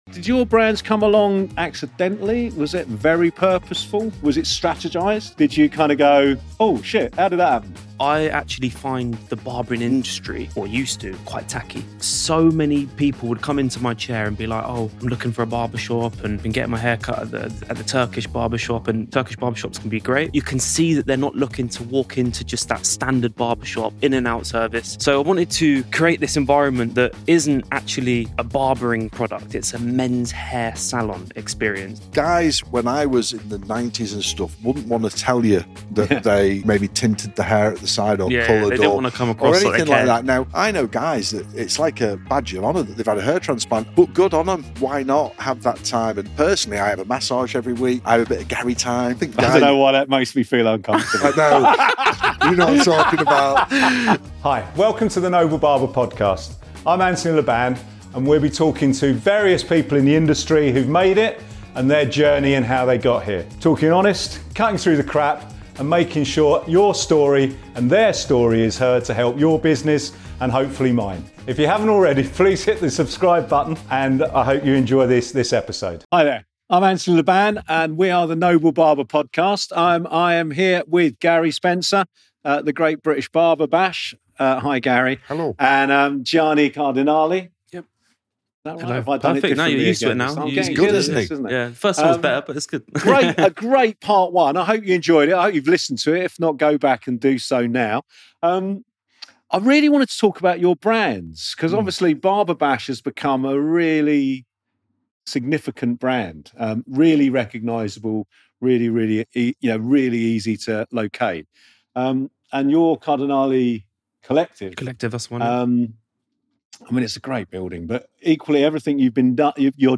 Joined weekly by industry professionals to talk about barbering, becoming and maintaining a barber shop, hiring, employment, money and cutting through the crap of the industry.